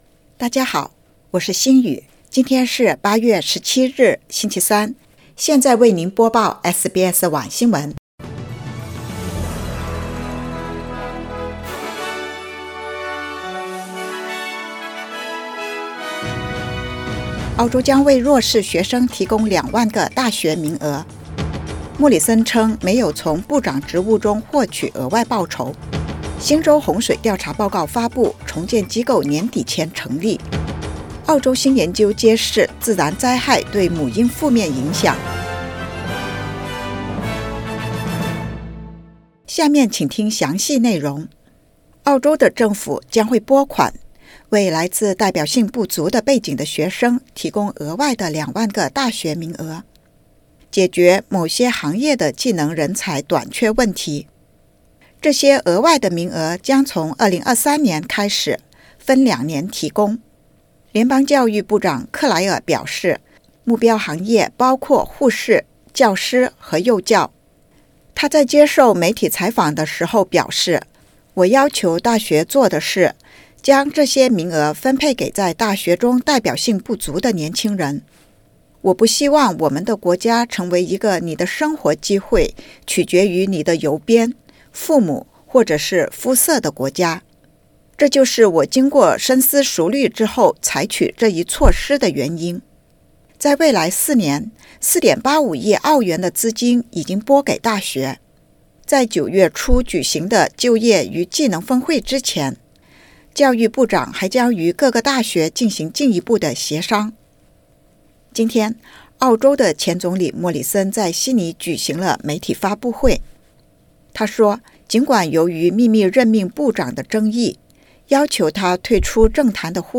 SBS晚新闻（2022年8月17日）